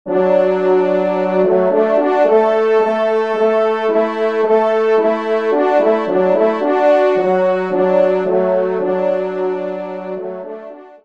Genre : Divertissement pour Trompes ou Cors
Pupitre 2°Trompe